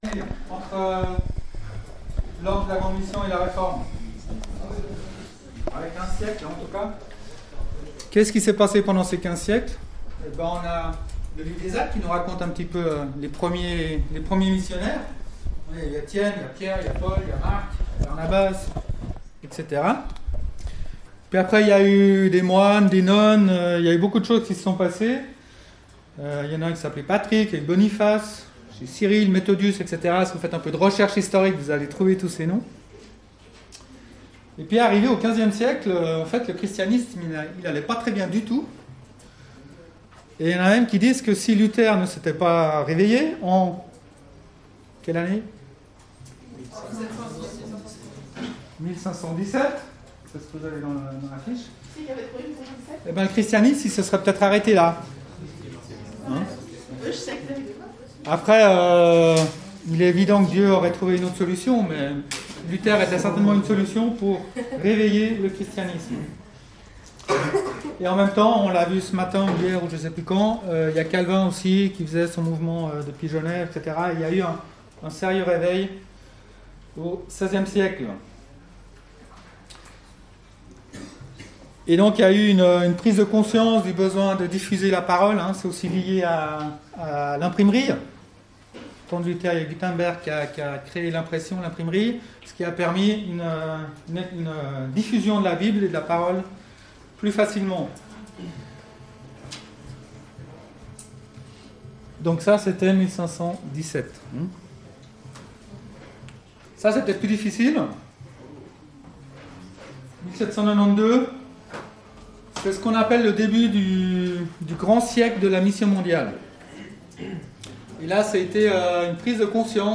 Pâques 2019 - Ateliers